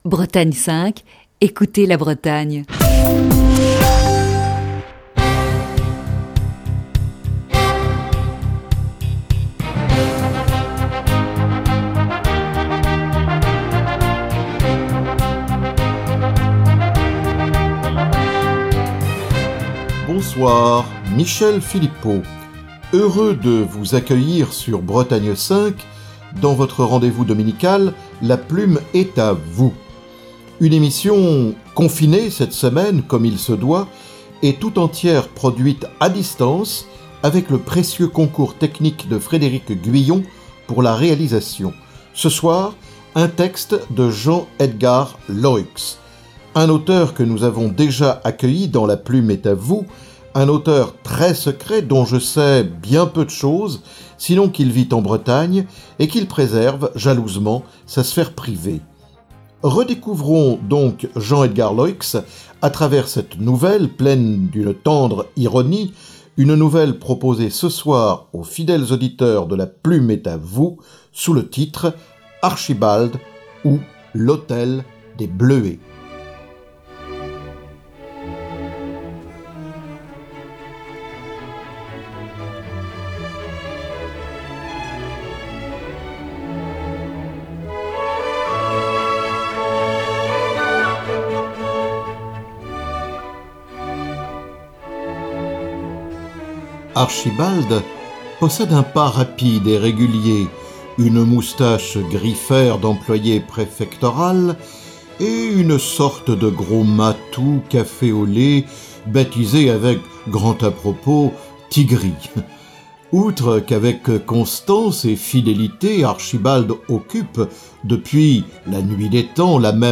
lit une nouvelle